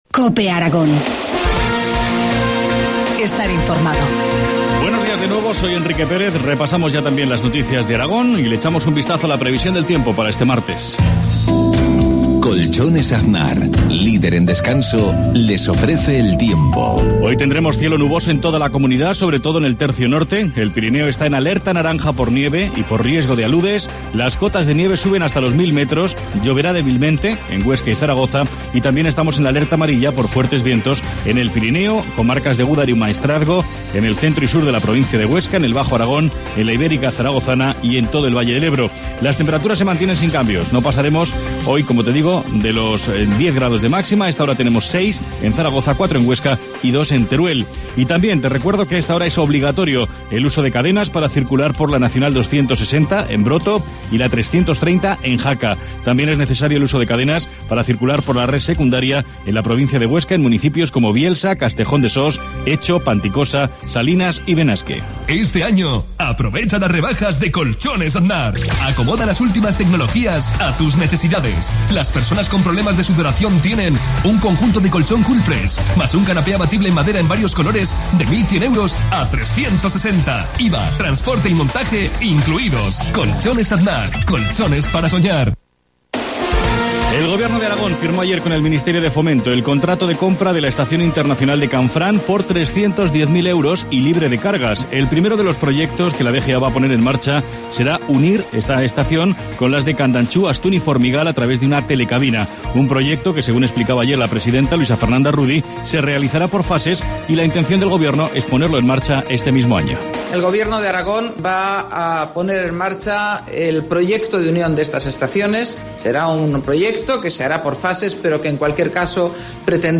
Informativo matinal, martes 15 de enero, 7.53 horas